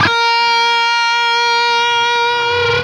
LEAD A#3 CUT.wav